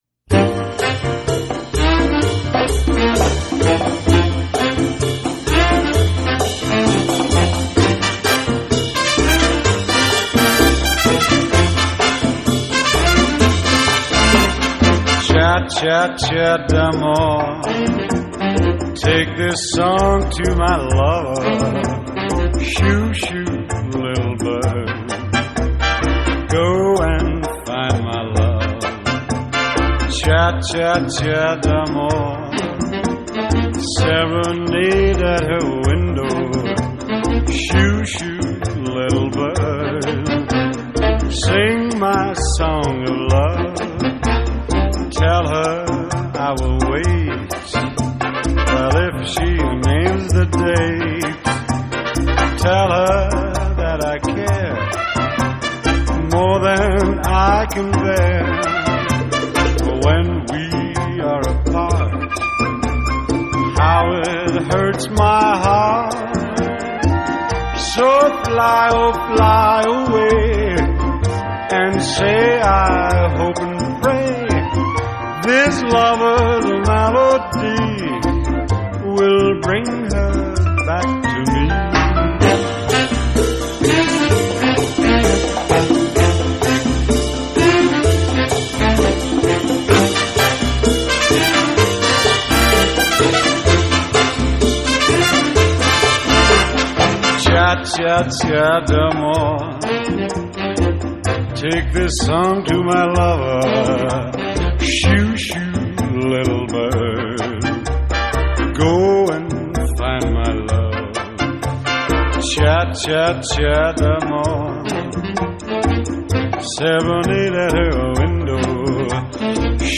Latin Pop, Jazz